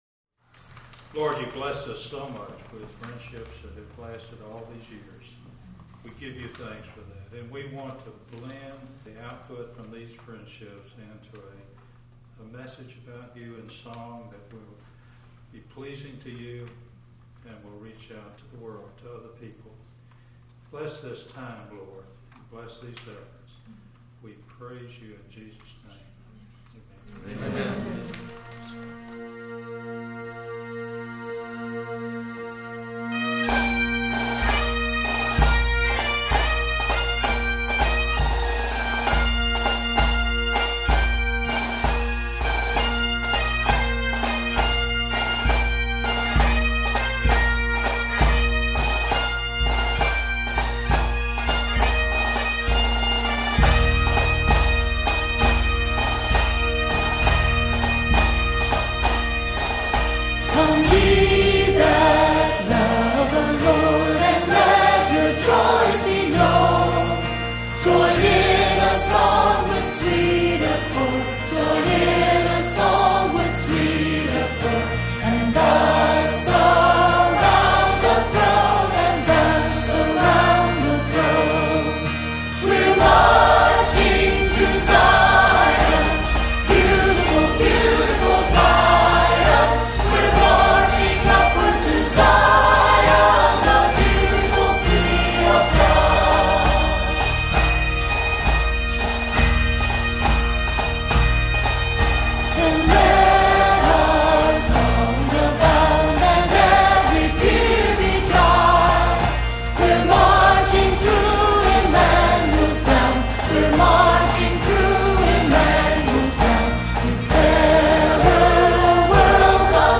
(BTW – I love bagpipes.